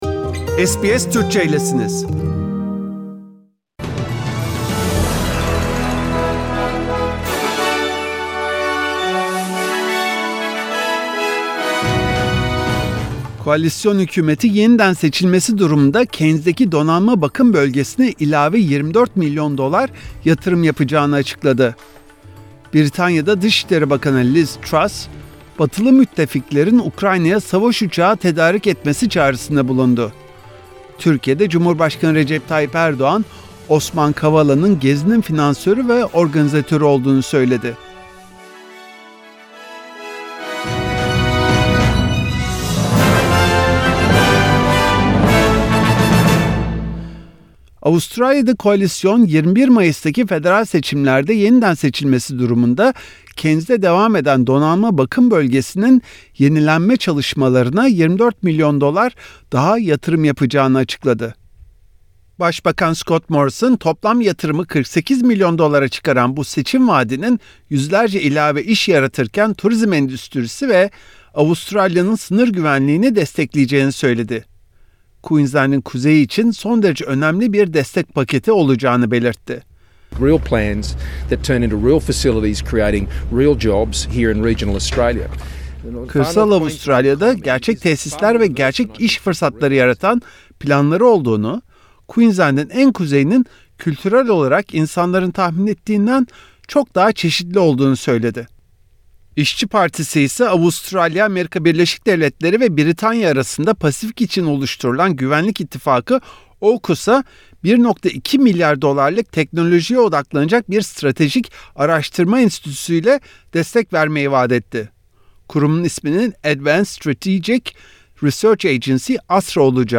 SBS Türkçe Haberler 28 Nisan